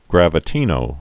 (grăvĭ-tēnō)